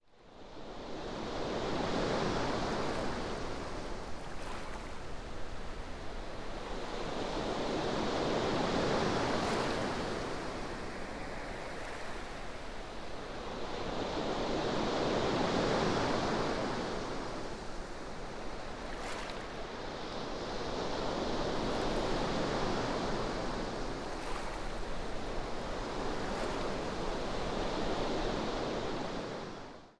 Die Audioaufnahmen der Ausgabe 1 haben das kraftvolle Rauschen von Meereswellen als Naturgeräusche unterlegt.
2. In der zweiten Aufnahme ist das Meeresrauschen den Silent Subliminals unterlegt und sorgt für wohlige Entspannung.
Silent Subliminal nur mit Naturgeräusch